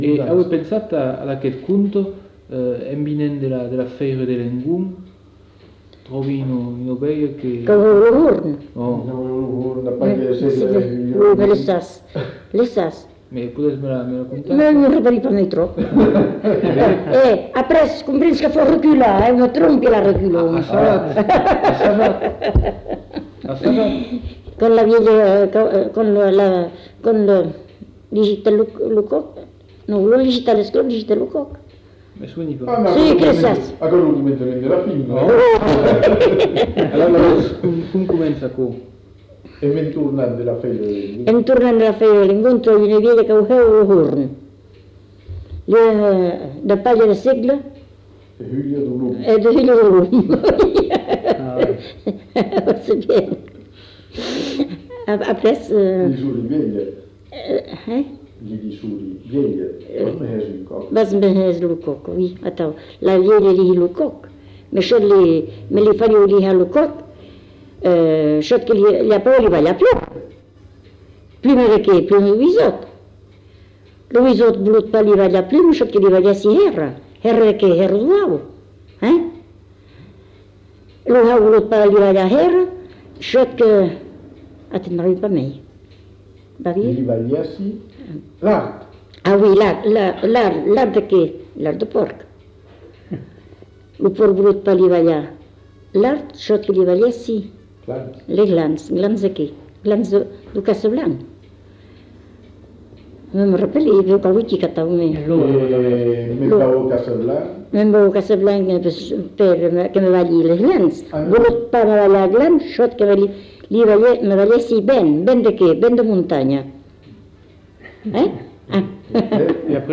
Lieu : Cazalis
Genre : conte-légende-récit
Effectif : 1
Type de voix : voix de femme
Production du son : parlé
Classification : récits cumulatifs : randonnées